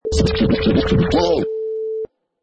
Sound sample: DJ Scratch 2
Pro DJ scratch
Product Info: 48k 24bit Stereo
Category: Musical Instruments / Turntables
Try preview above (pink tone added for copyright).
DJ_Scratch_2.mp3